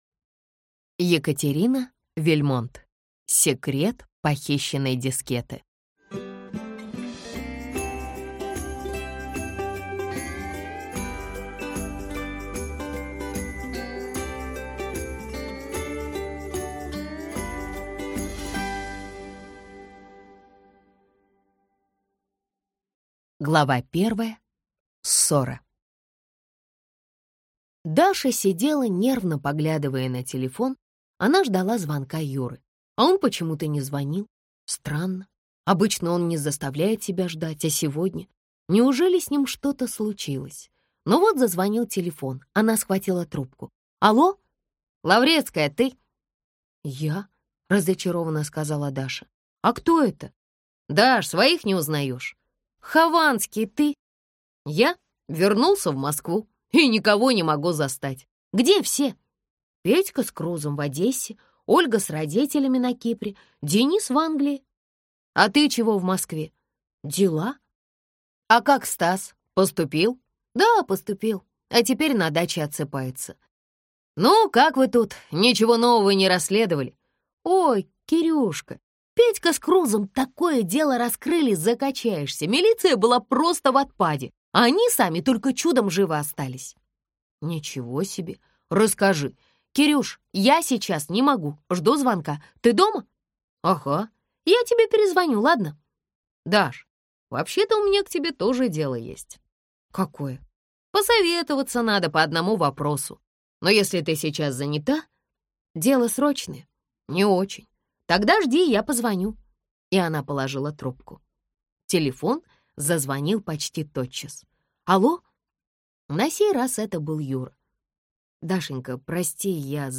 Аудиокнига Секрет похищенной дискеты | Библиотека аудиокниг